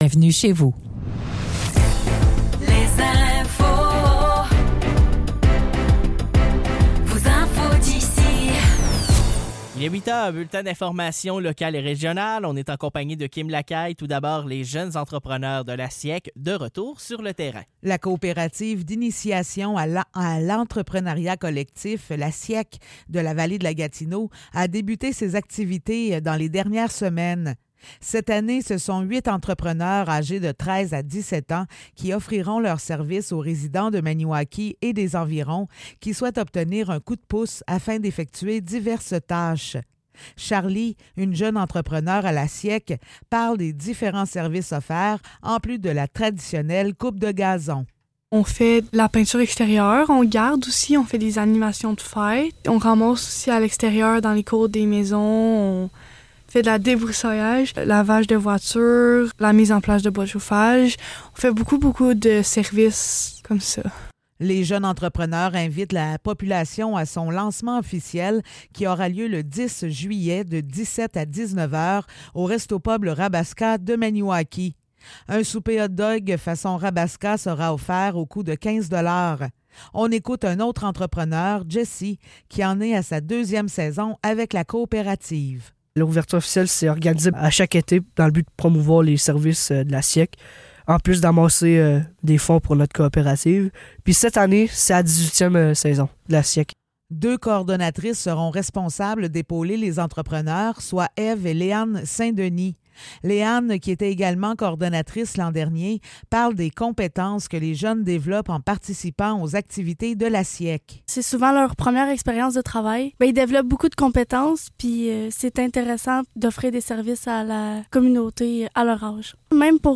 Nouvelles locales - 8 juillet 2024 - 8 h